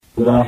مقام البيات ( تواشيح ) ـ فرقة القدر - لحفظ الملف في مجلد خاص اضغط بالزر الأيمن هنا ثم اختر (حفظ الهدف باسم - Save Target As) واختر المكان المناسب